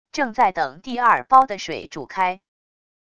正在等第二煲的水煮开wav音频生成系统WAV Audio Player